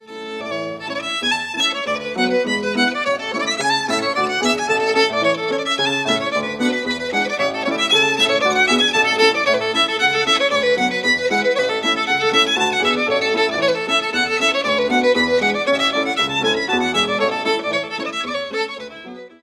The Fiddler and His Art (CDTRAX 9009 MONO) has been one of the best selling records released by the University of Edinburgh’s School of Scottish Studies from its huge audio archive and issued as part of its acclaimed Scottish Tradition Series .
We were both fascinated by the unique bowing techniques used by the artists.
Not all tracks are included here and – with regard to the audio – only the opening of each item is presented.
Waverley Ball (reel